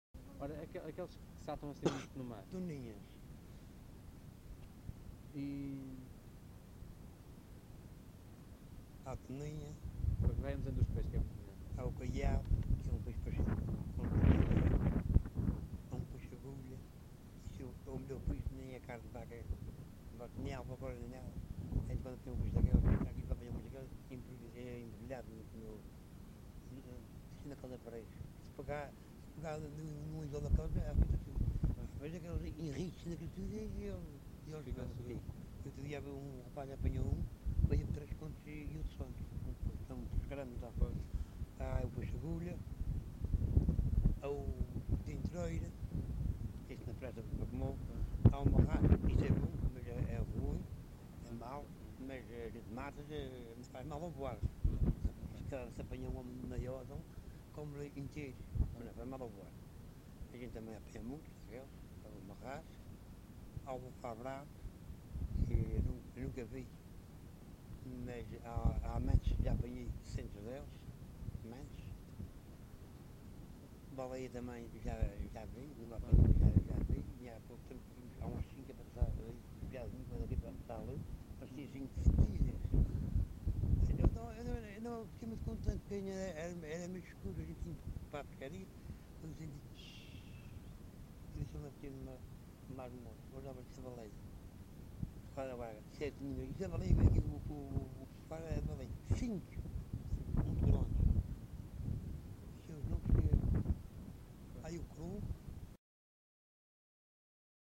LocalidadePraia da Vitória (Praia da Vitória, Angra do Heroísmo)